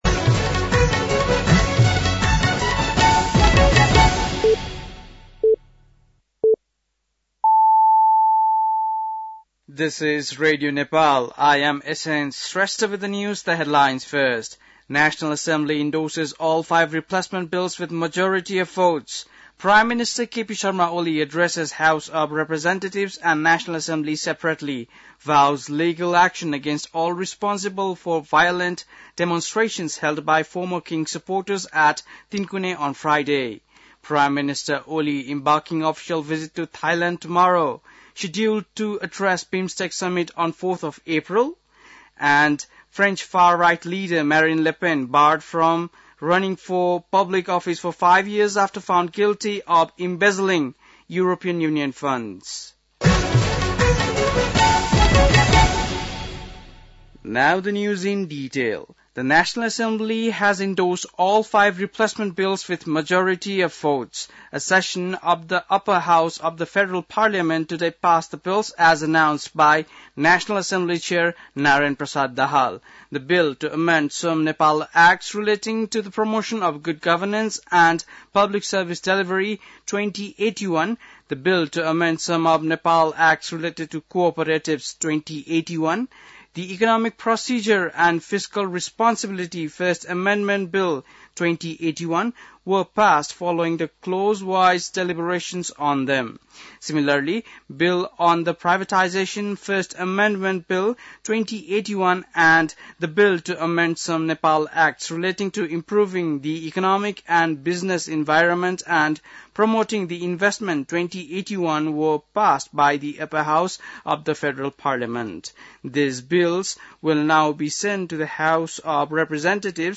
बेलुकी ८ बजेको अङ्ग्रेजी समाचार : १८ चैत , २०८१
8-pm-news.mp3